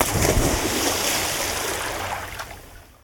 watersplash.mp3